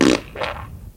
文件夹里的屁 " 屁 29
描述：从freesound上下载CC0，切片，重采样到44khZ，16位，单声道，文件中没有大块信息。
Tag: 喜剧 放屁 效果 SFX soundfx 声音